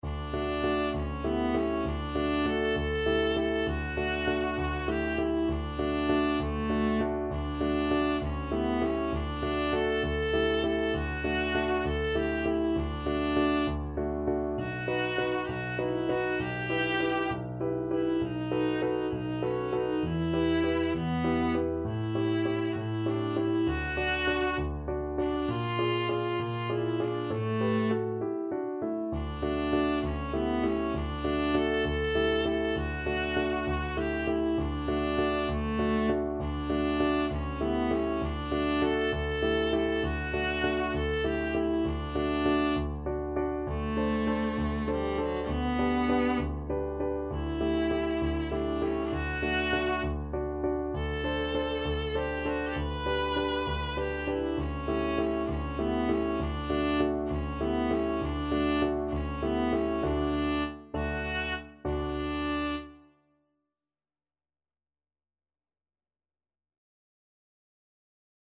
Viola
D major (Sounding Pitch) (View more D major Music for Viola )
6/8 (View more 6/8 Music)
. = 66 No. 3 Grazioso
Classical (View more Classical Viola Music)